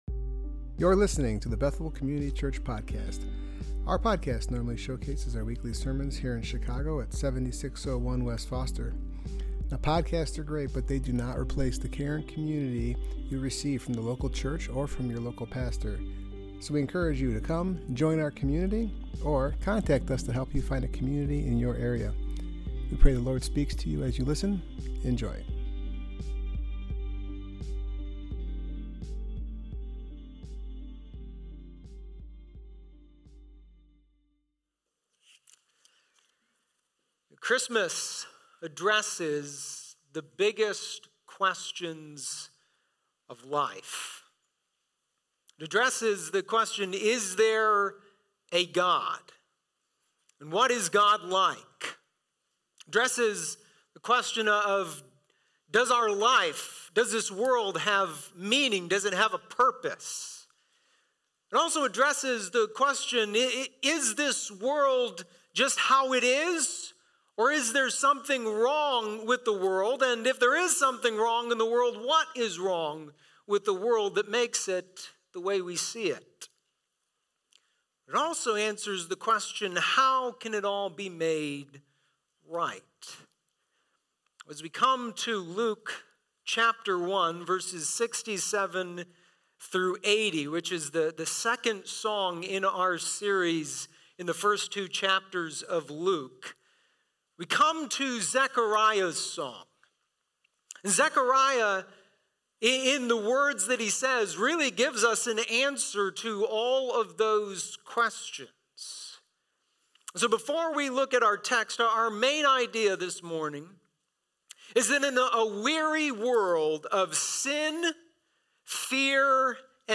Passage: Luke 1:67-80 Service Type: Worship Gathering